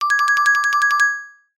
comedy_marimba_flutter_or_shake_long_high_pitch